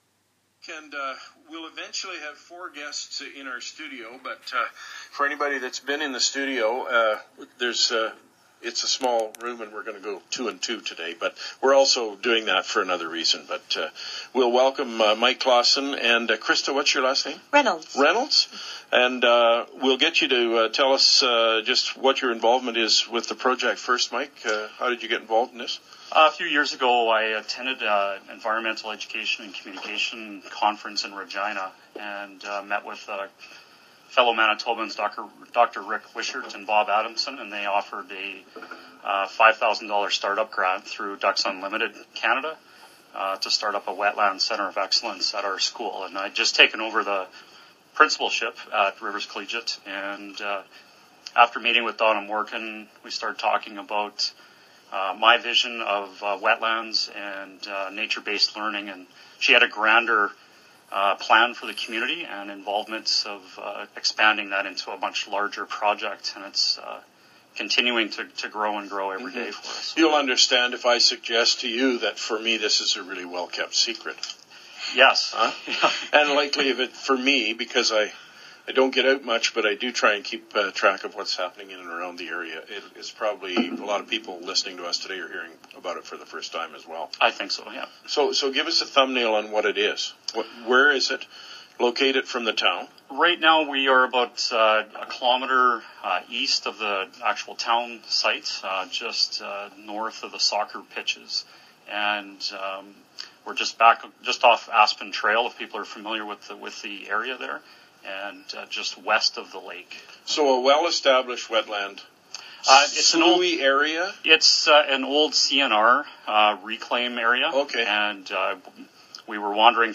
rivers-wetland-interview-CKLQ.m4a